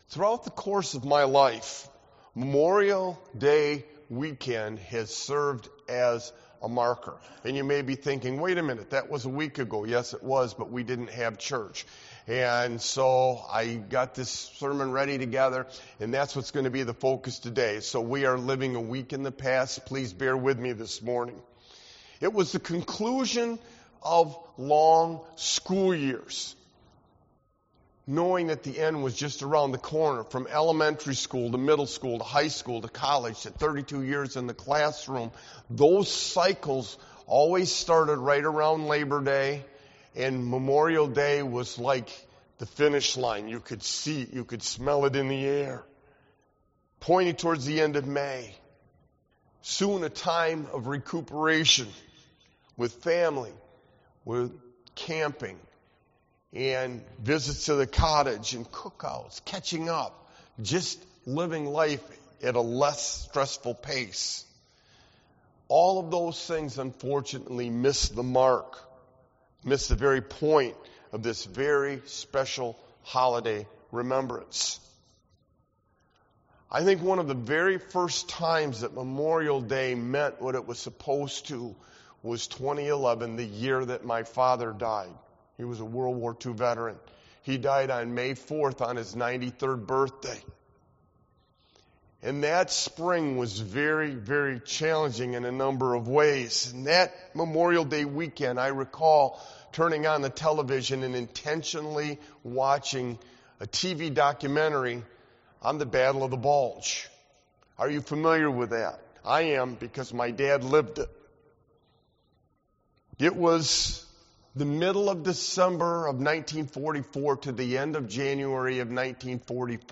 Sermon-Sacrifice-6621.mp3